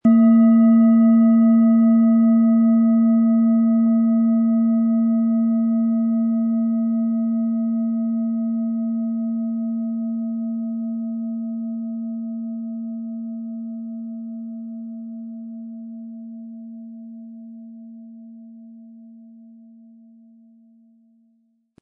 Planetenschale® Wohlige Energie im Brustbereich & Emotionen wahrnehmen mit Hopi-Herzton & Mond, Ø 16,1 cm, 600-700 Gramm inkl. Klöppel
• Tiefster Ton: Mond
PlanetentöneHopi Herzton & Mond
MaterialBronze